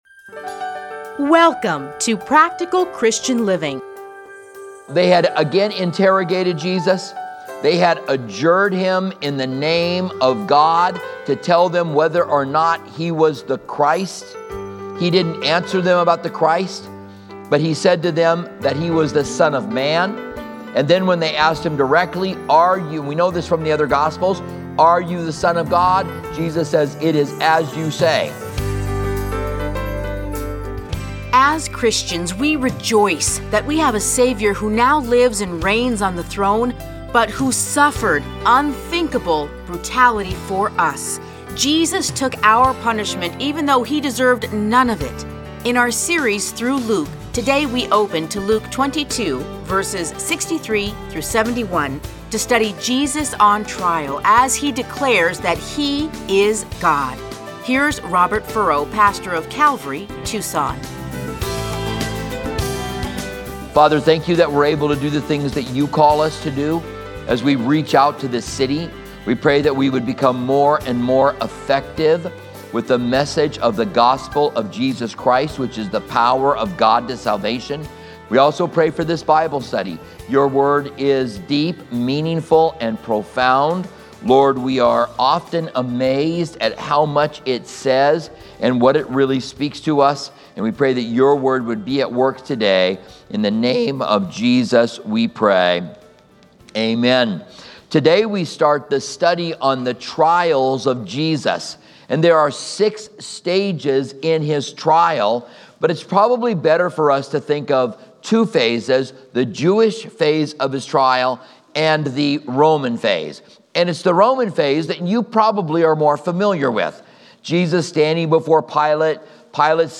Listen to a teaching from Luke 22:63-71.